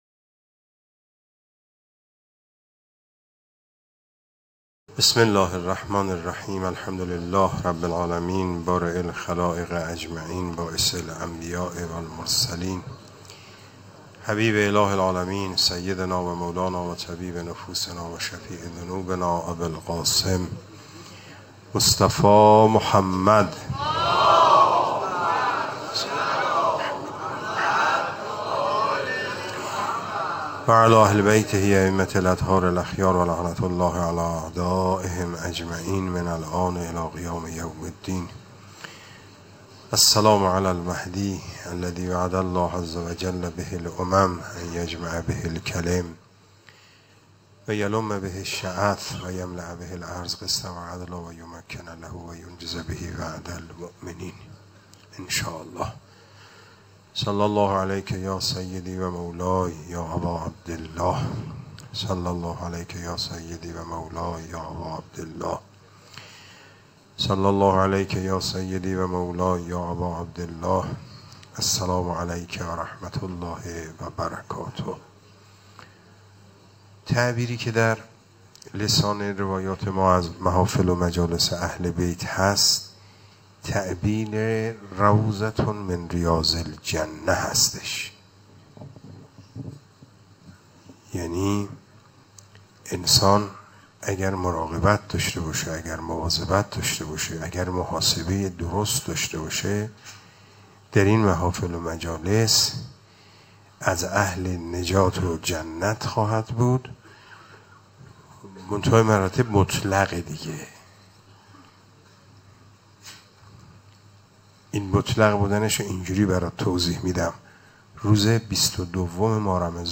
سخنرانی | افراد با عملکردشان در این دنیا می‌توانند جزئی از خانواده اهل بیت باشند
حسینیه صنف لباس فروشان تهران